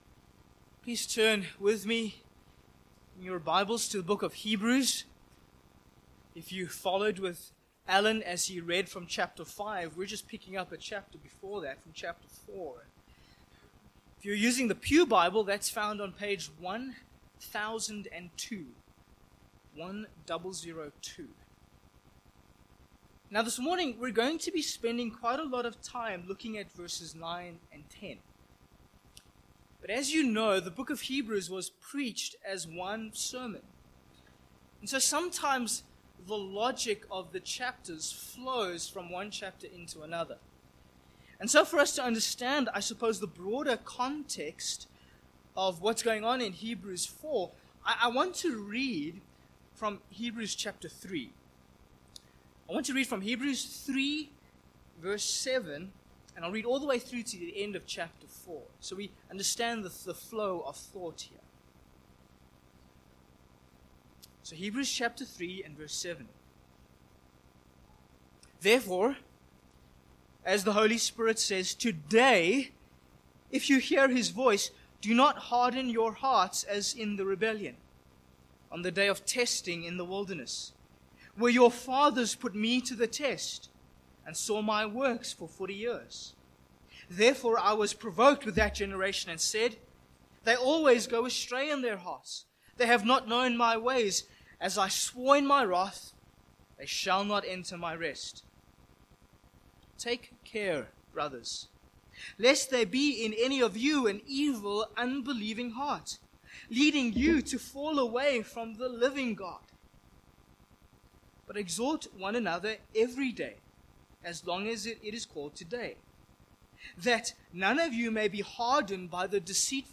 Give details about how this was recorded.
The Lord's Day Passage: Hebrews 4:1-16 Service Type: Morning « The Lord’s Day In Christ